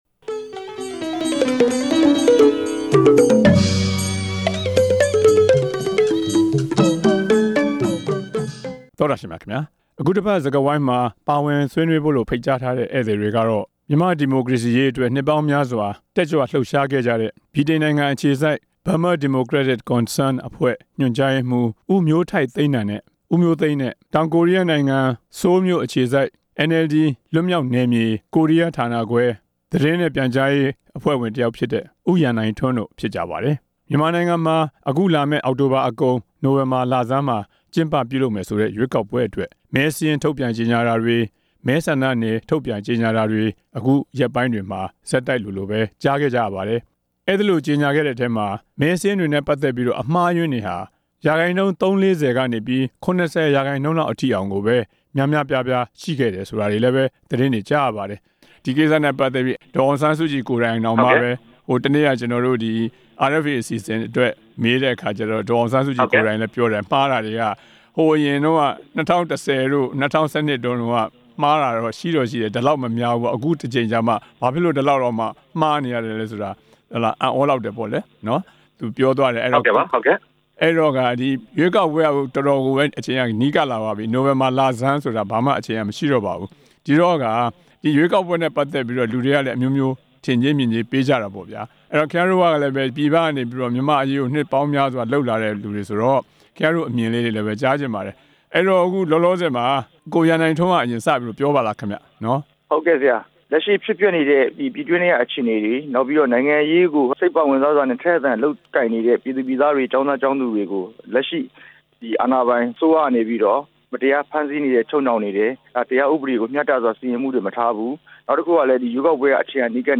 ရွေးကောက်ပွဲအတွက် အာဏာပိုင်တွေ ပြင်ဆင်နေတဲ့အကြောင်း ဆွေးနွေးချက်